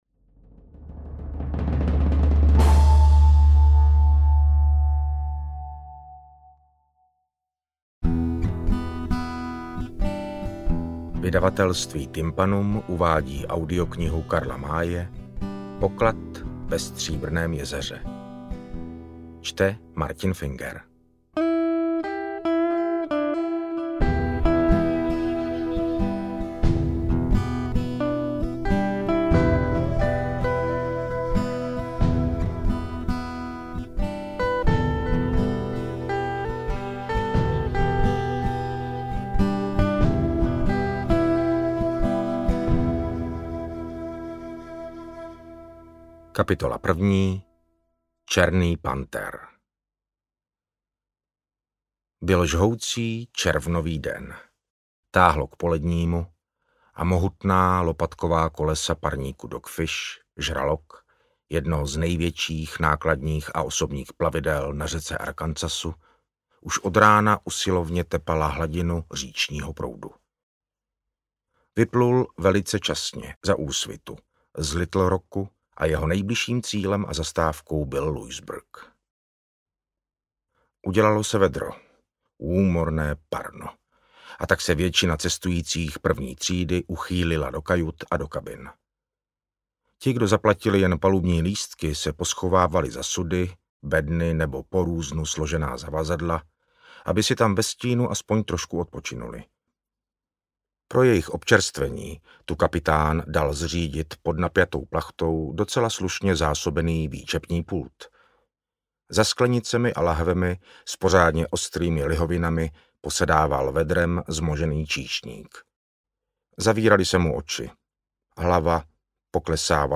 Interpret:  Martin Finger
AudioKniha ke stažení, 45 x mp3, délka 15 hod. 58 min., velikost 873,3 MB, česky